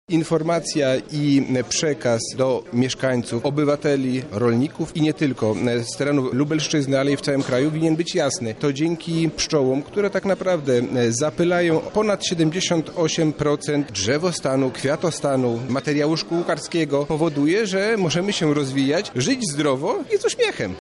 To właśnie ochrona najbardziej pracowitych owadów na świecie, była tematem środowej konferencji organizowanej przez Lubelski Urząd Wojewódzki. Pszczoły są ważne dla regionu, o tym mówił wicewojewoda lubelski Robert Gmitruczuk.